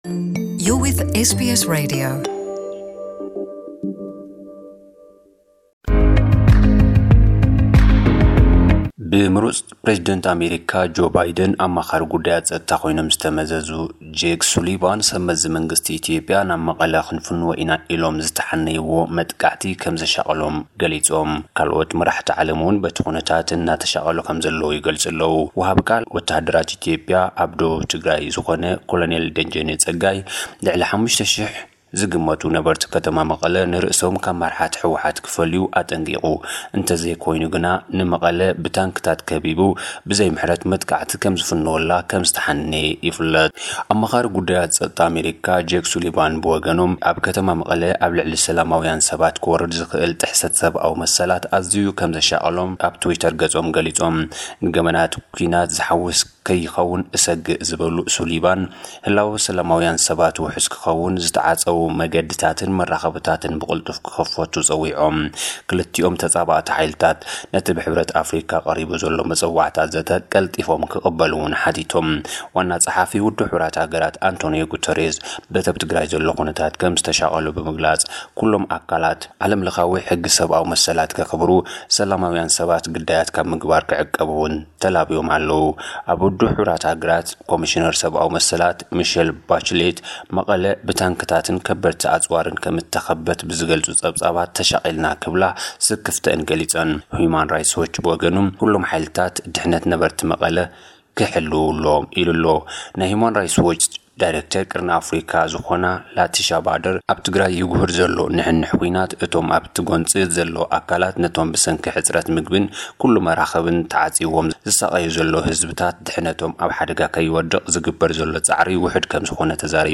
ጸብጻባት ዜና